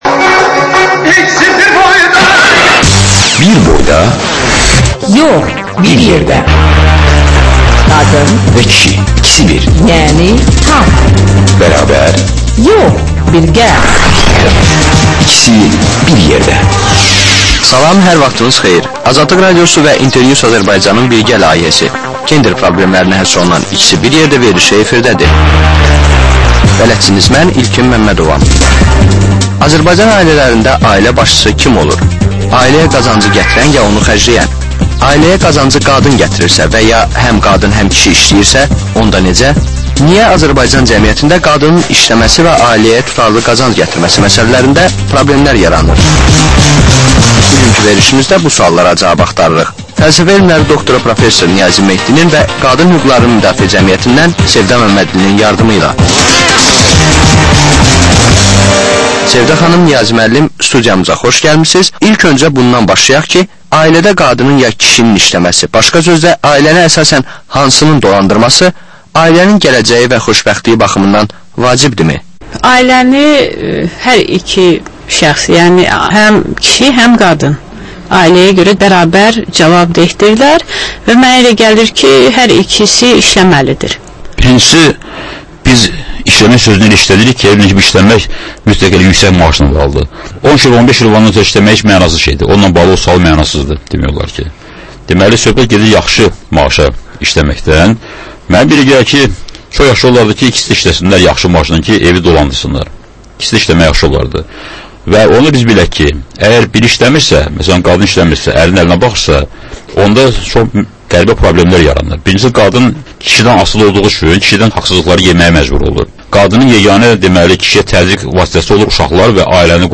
Həftənin aktual məsələsi haqda dəyirmi masa müzakirəsi